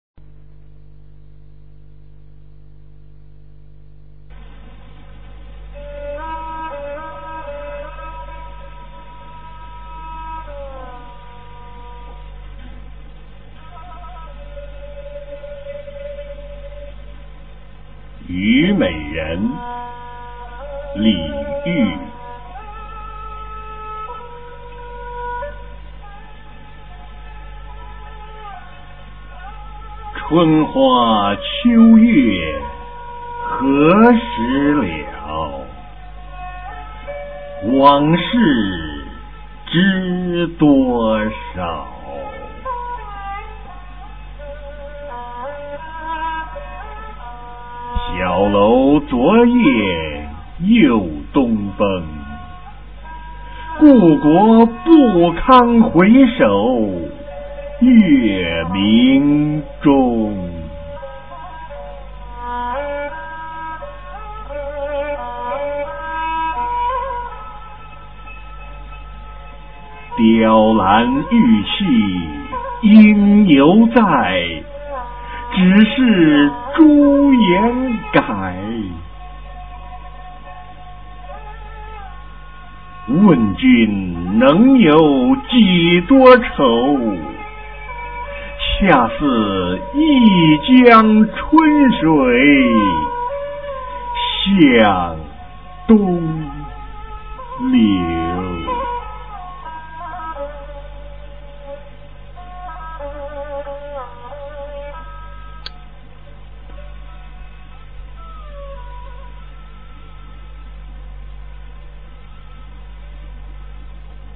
李煜《虞美人·春花秋月何时了》原文和译文（含赏析、朗读）　/ 李煜